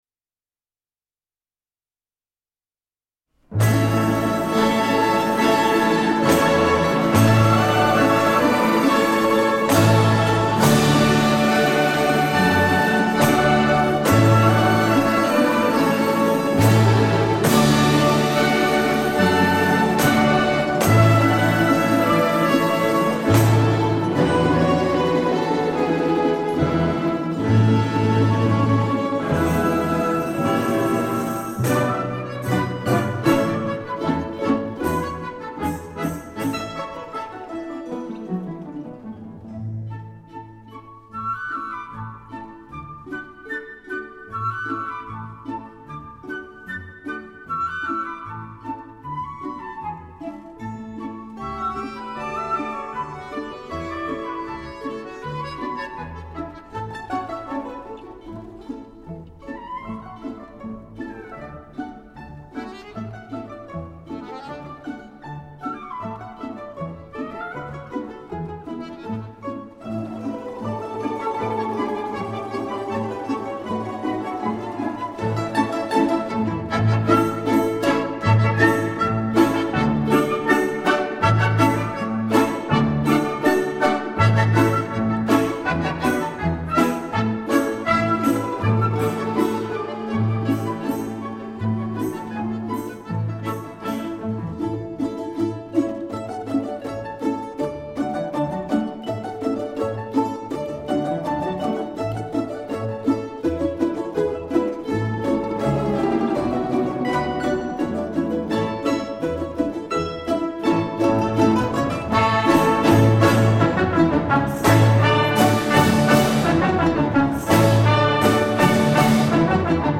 Belorusskiy_narodnyy_tanec-Kryzhachok.mp3